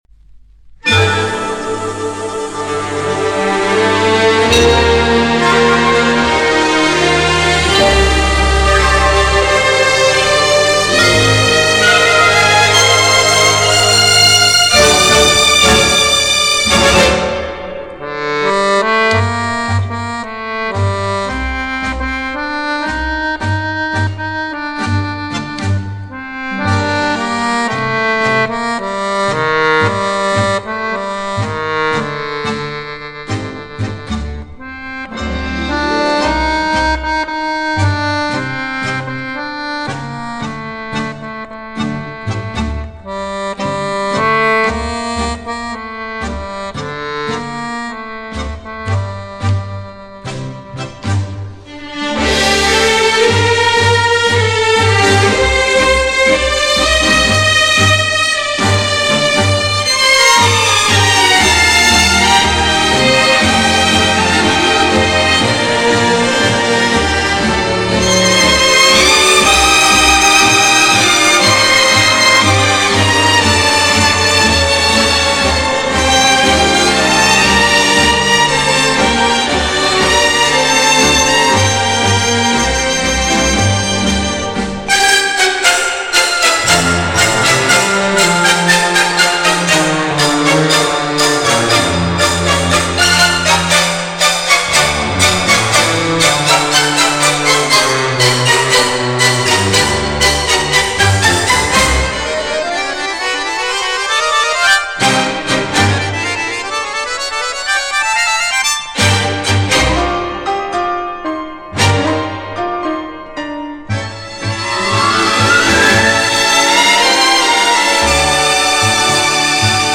是由日本歌曲的改编的探戈曲